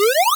doop.wav